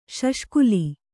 ♪ śaṣkuli